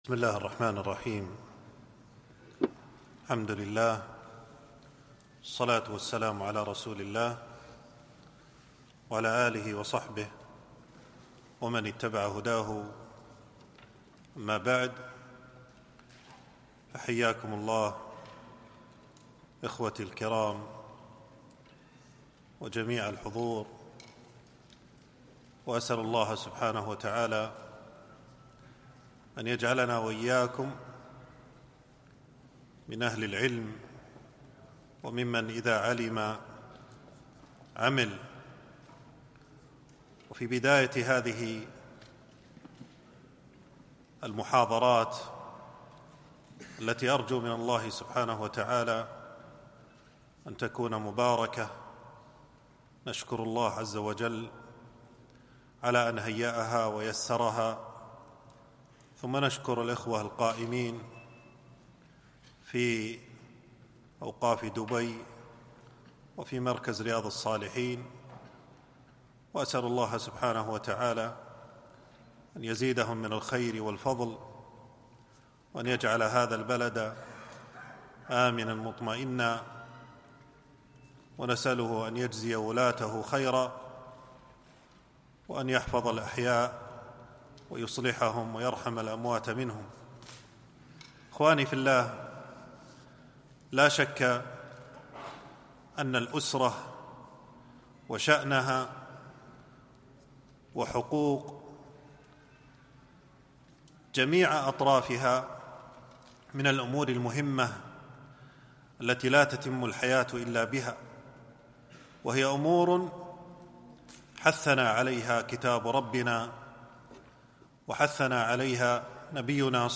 محاضرة - حق الوالدين - دروس الكويت